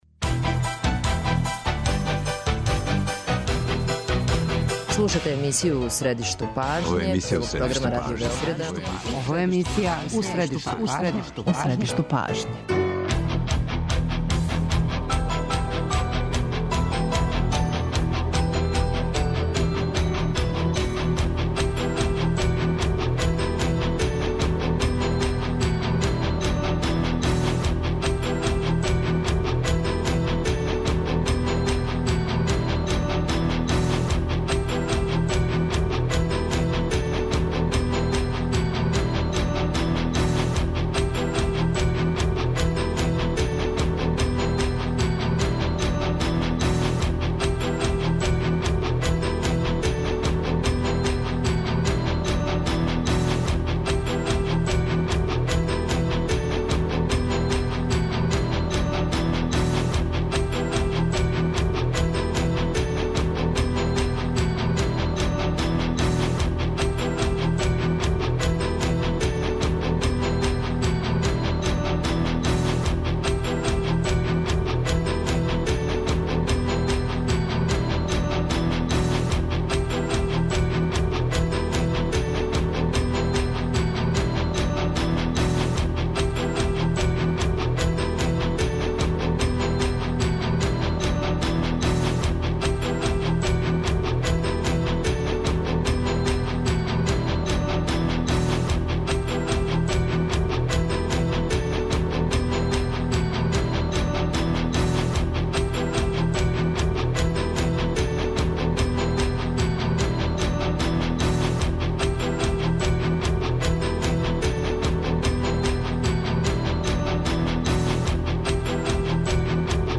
Како грађани да остваре своја права, ако су угрожена, и како извршити притисак на институције, ако не раде свој посао? Гост емисије је заштитник грађана, Саша Јанковић, кога ћемо између осталог питати и какав је епилог приче о децембарским рачунима за струју?